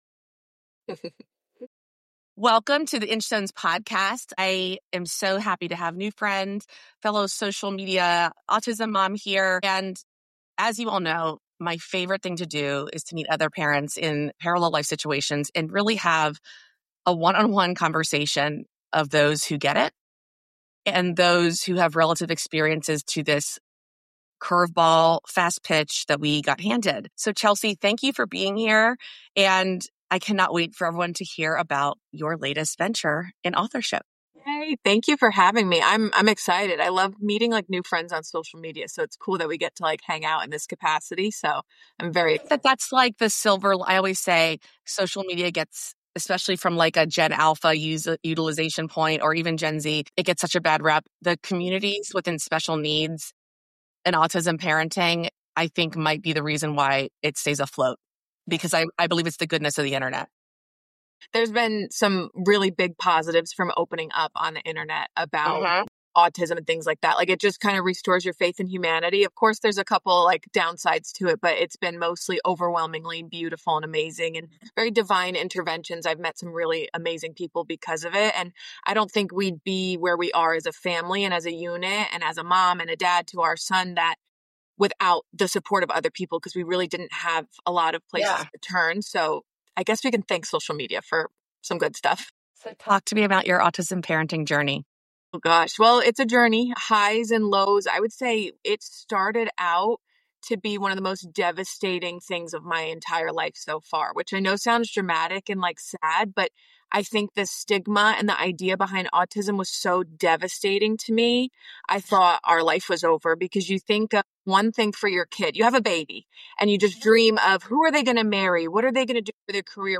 Books, Affirmations, and Autism: A Conversation for Moms Like Us